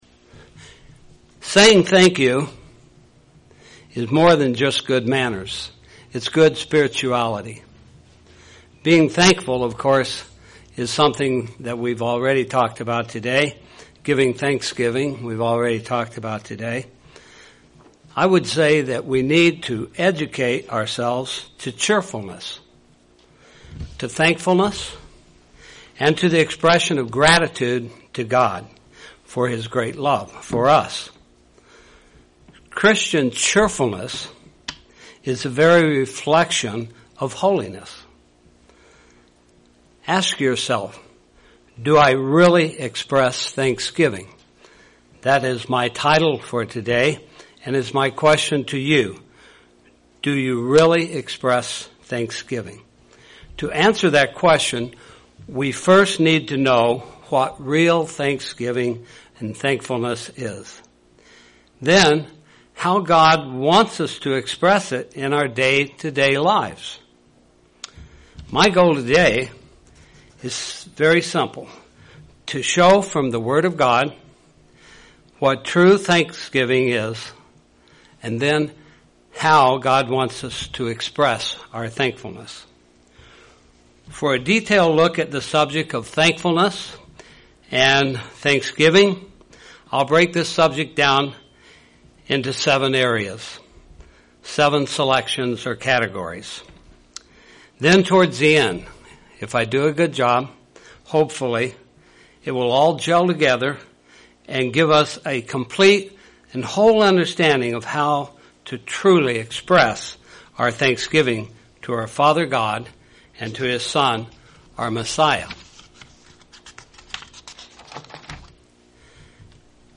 In this sermon, the speaker shows what the Bible has to say about what thanksgiving is and how God wants us to express it.
Given in Springfield, MO